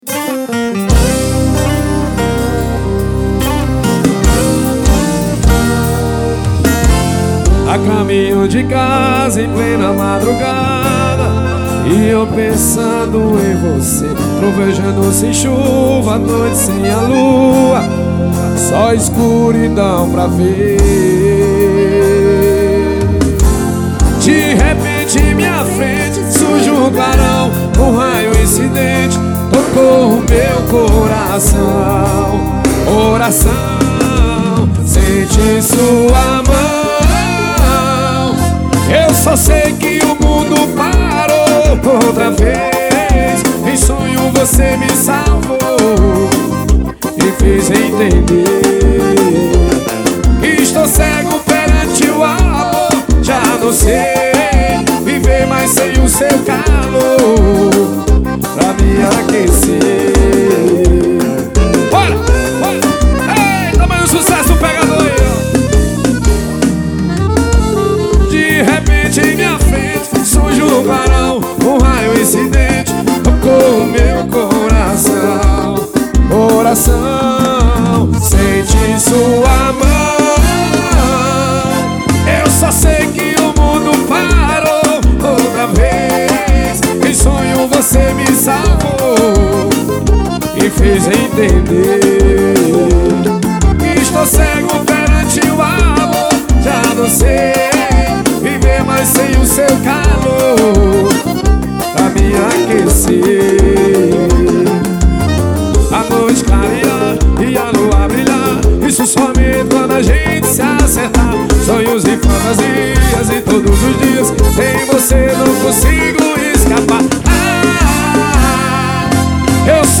FORRO PEGADO.